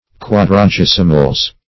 Search Result for " quadragesimals" : The Collaborative International Dictionary of English v.0.48: Quadragesimals \Quad`ra*ges"i*mals\, n. pl. Offerings formerly made to the mother church of a diocese on Mid-Lent Sunday.
quadragesimals.mp3